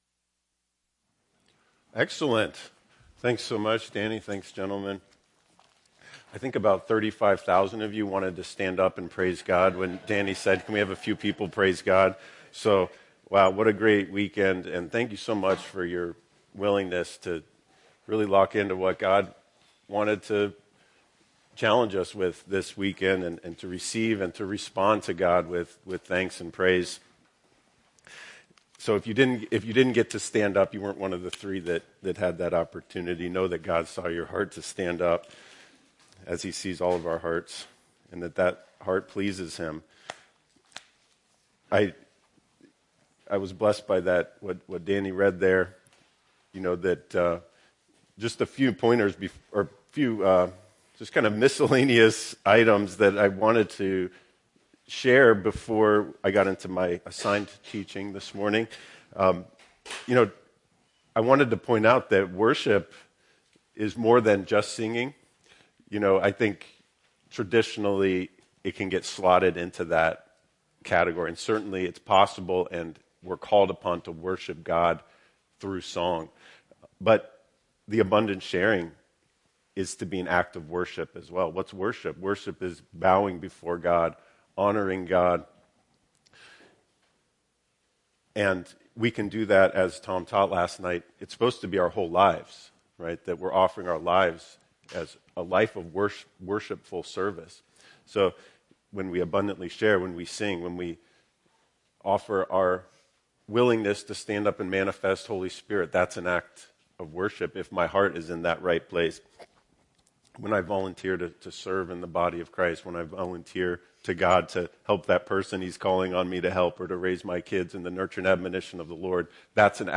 Part 5 of 5 in a series of weekend teachings on the topic of God, Our Creator. God created the world and every creature in it. It is by His will that mankind came to exist and it is by His will that we were created anew in Christ.